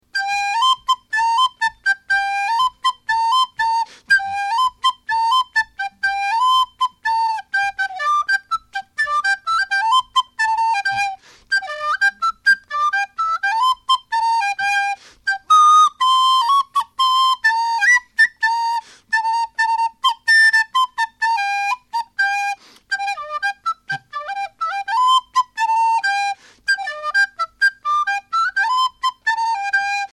Known more for his blues playing he surprised us by then playing a Bill Conroy whistle tune on the recorder.
It's all at Ashington Folk Club!